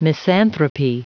Prononciation du mot misanthropy en anglais (fichier audio)
Prononciation du mot : misanthropy